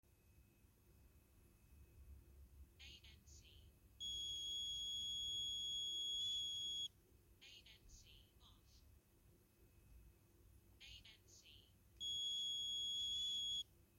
Als ANC aanstaat en ik druk op het siliconen dinges van het linker oortje op een bepaalde manier dan gaat ie fluiten.
BEWARE_LOUD_TONE_Creative_Aurvana_Ace_left_earbud.mp3